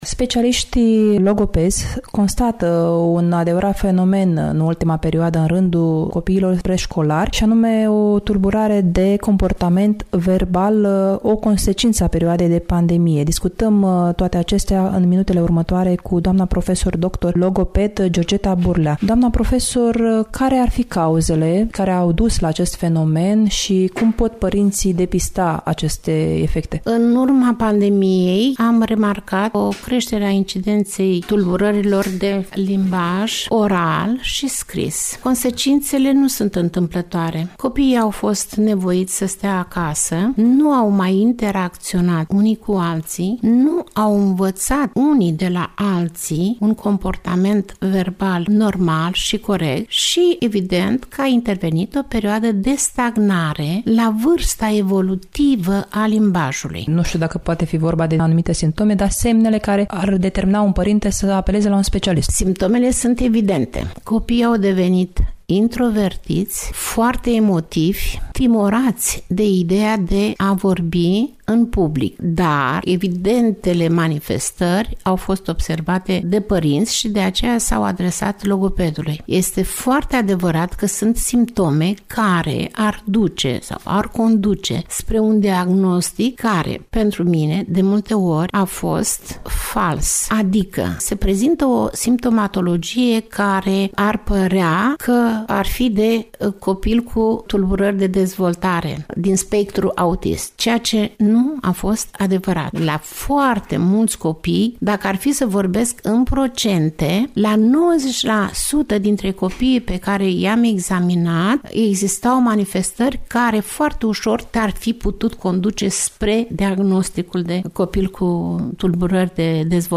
(INTERVIU) Iași: Tulburări de limbaj în rândul preșcolarilor